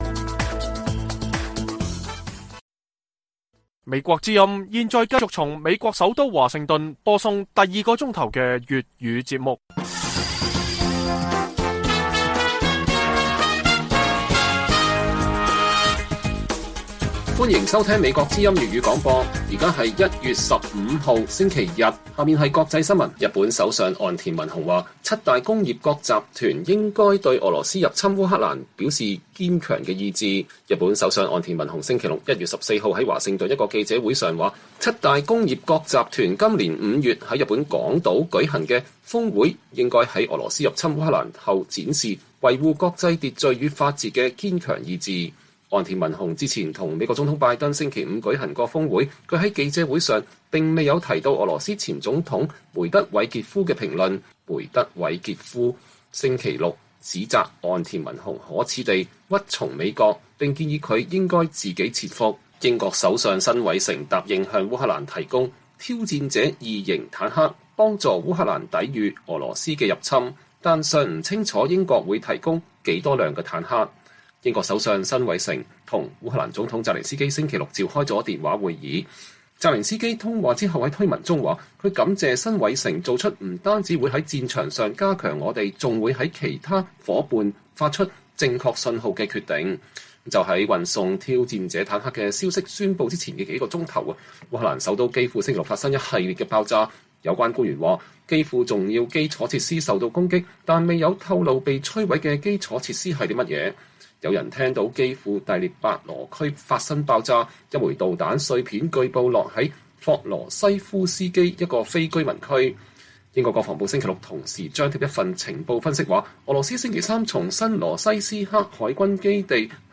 粵語新聞 晚上10-11點 ： 八天，五位親人離世，都不是以新冠之名